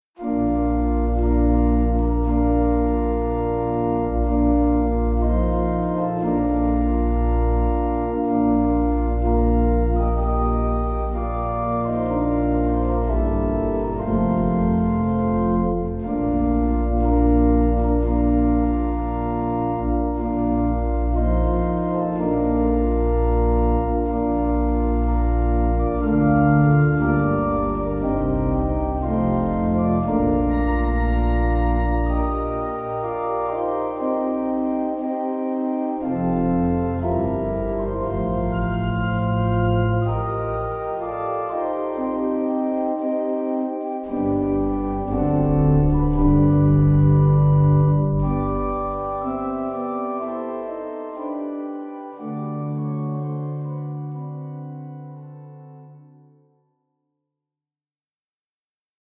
●リードオルガン 楽譜(手鍵盤のみで演奏可能な楽譜)